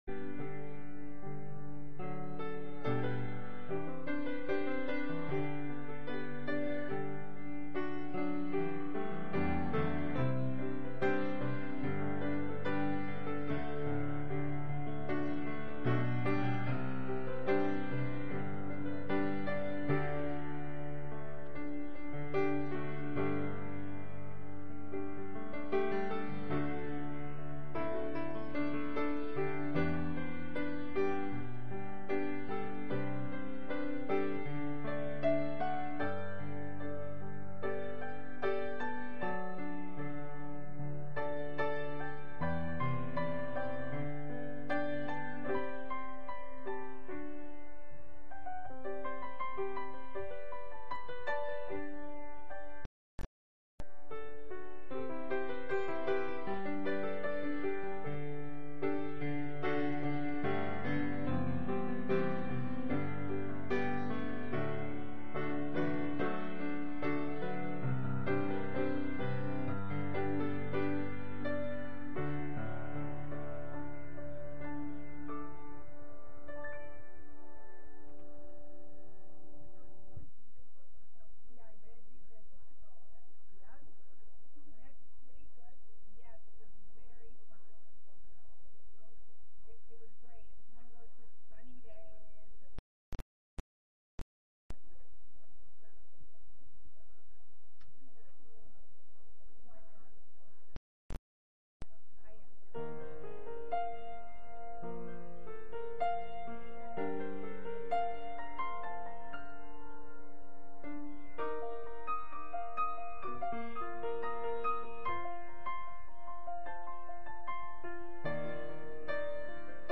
Sermons | Grace Lutheran Church
From Series: "Sunday Worship"
Sunday-Service-Mar-3-2024.mp3